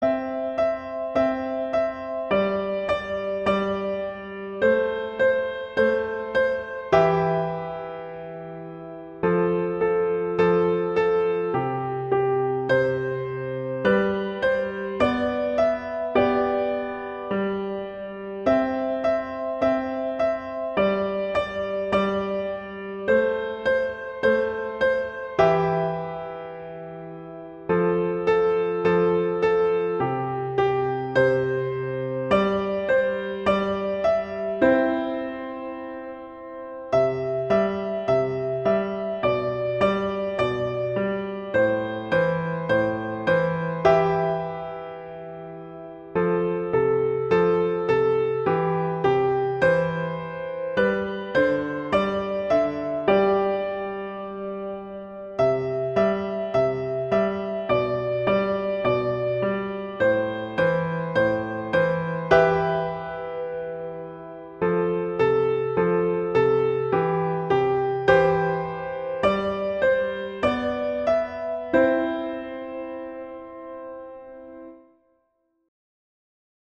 arrangement for piano solo
C major
♩=104 BPM